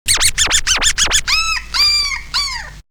Scratch 210.wav